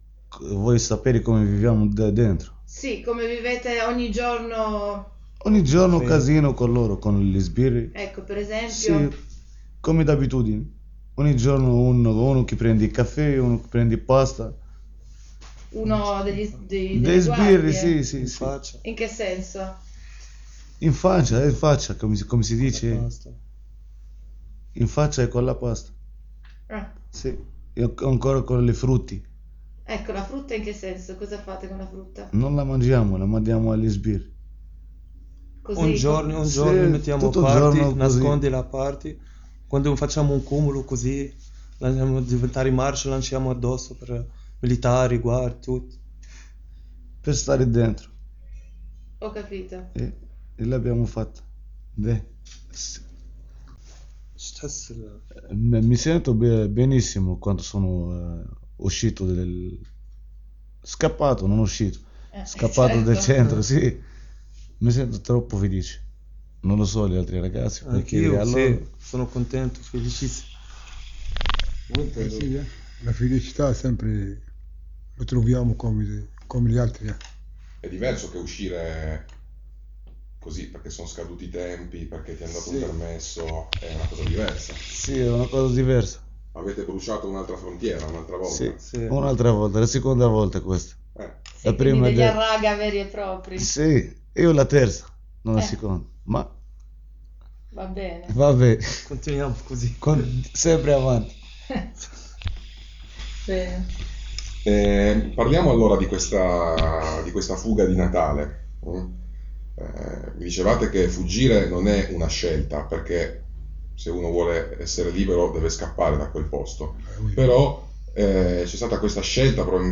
Intervista con gli evasi
Questa mattina su Radio Blackout 105.250FM, durante la trasmissione Macerie (su Macerie) in onda tutti i giovedì dalle 10.30 alle 12.30, sono state mandate in onda due interviste a tre evasi dal Cie di Torino a Natale, e ad un altro evaso a Capodanno.
Ascolta la prima parte dell’intervista con tre reclusi evasi a Natale (9 min)